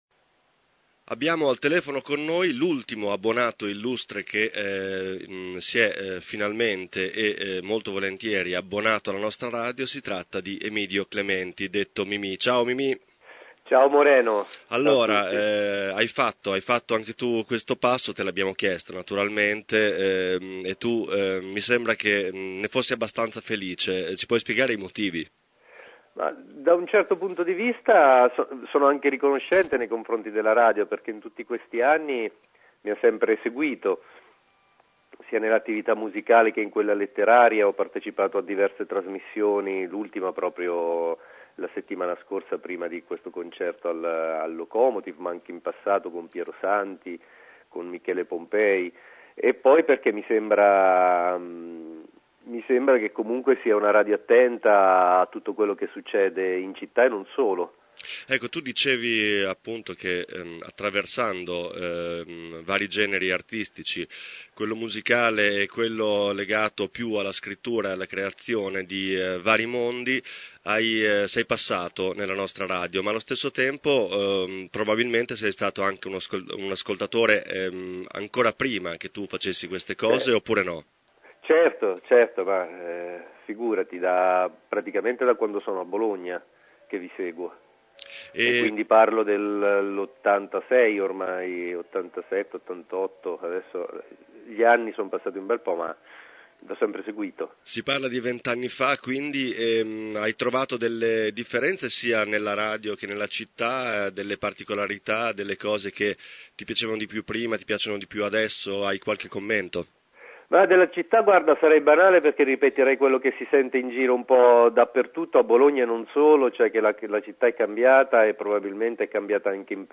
Mimì si è abbonato oggi alla Radio: Ascolta l’intervista clementi_sito